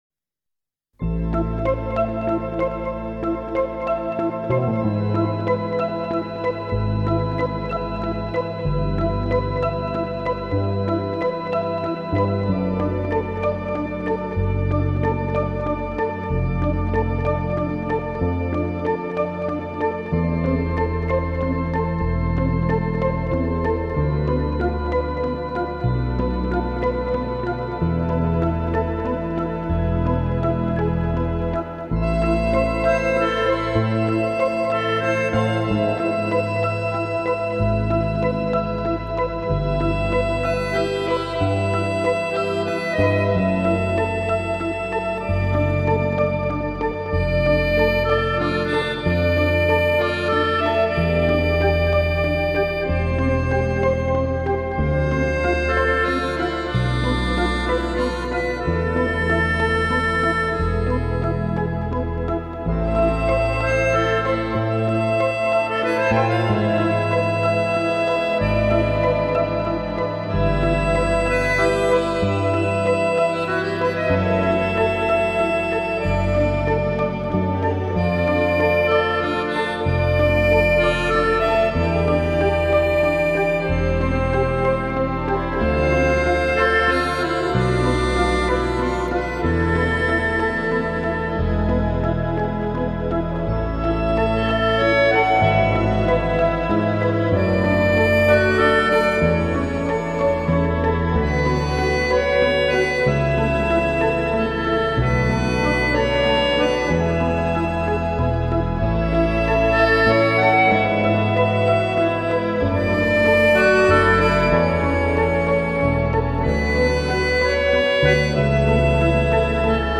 GenereWorld Music / New Age